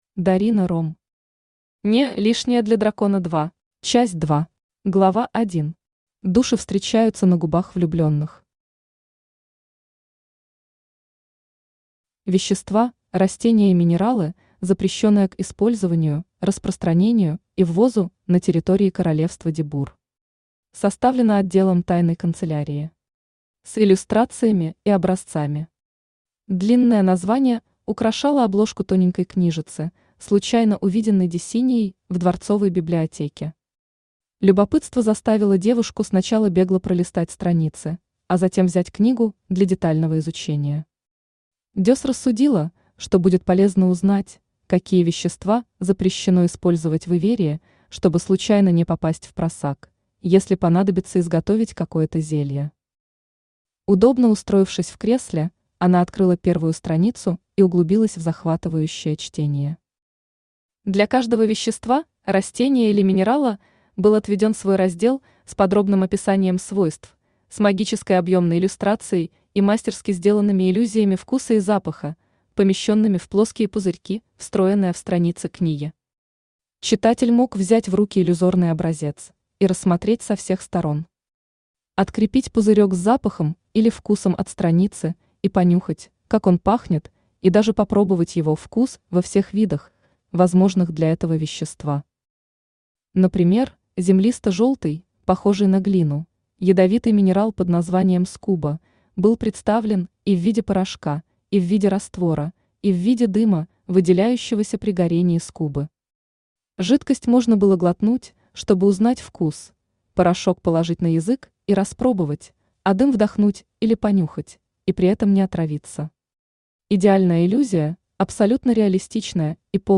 Aудиокнига (Не) Лишняя для дракона 2 Автор Дарина Ромм Читает аудиокнигу Авточтец ЛитРес.